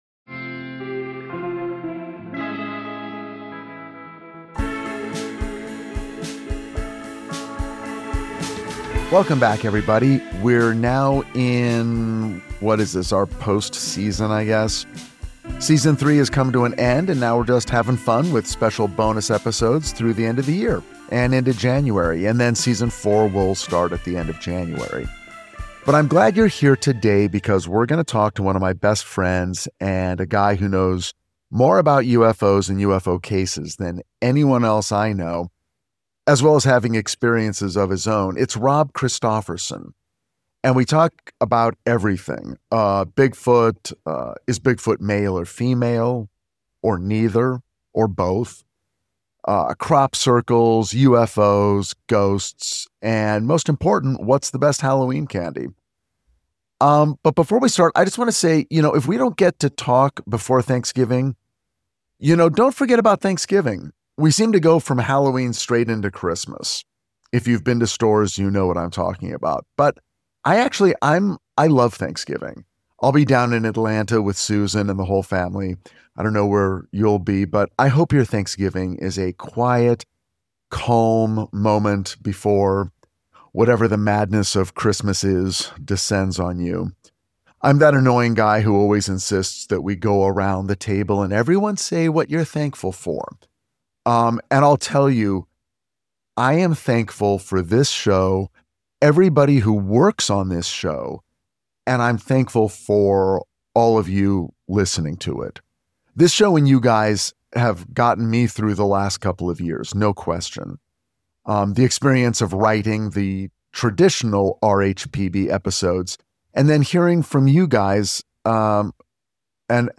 In this wide-ranging interview we get progressively weirder and wilder, touching on everything from Bigfoot to elves to ghosts to crop circles to UFO cults to alien abductions